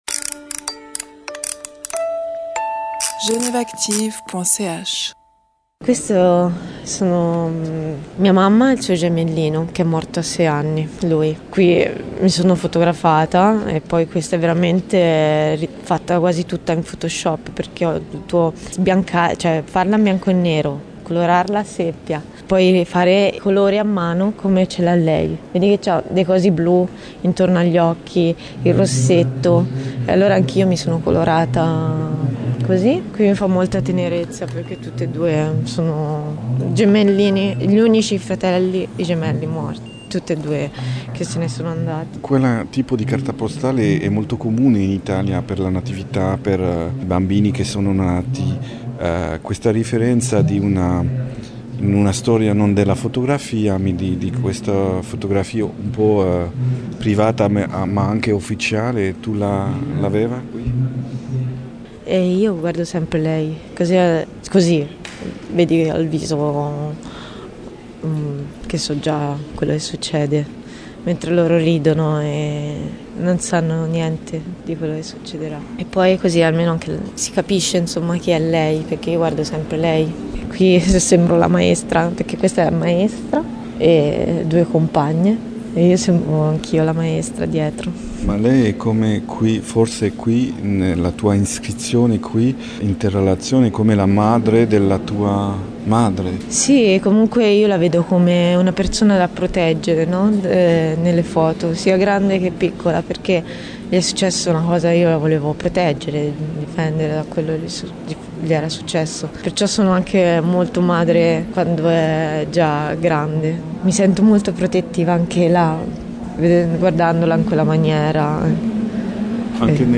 Une manière d’interroger un deuil impossible à  partir d’une sorte de figure d’ange de la consolation que la fille photographe joue. Entretien.